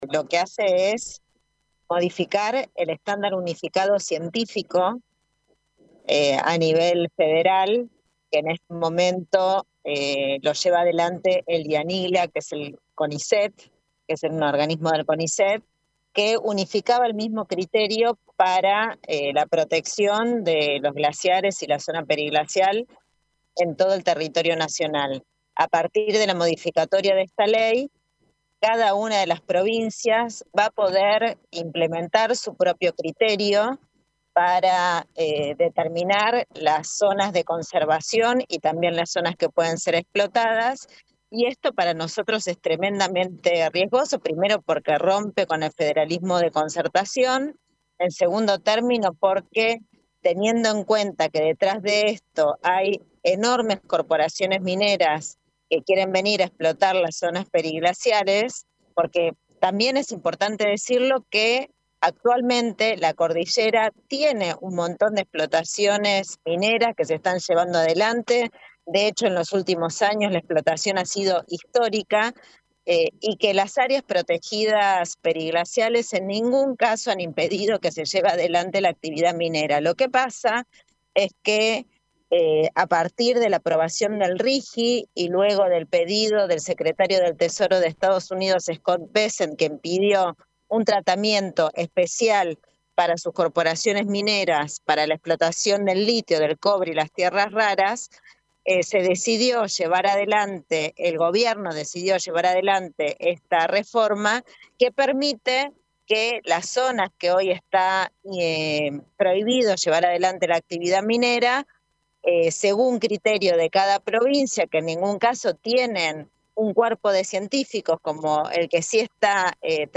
Gabriela Estévez, diputada nacional por el Frente de Todos, explicó detalles de la norma aprobada en el Congreso nacional. Dijo que se trata de una modificación peligrosa, ya que por detrás de esta ley hay intereses de las mineras.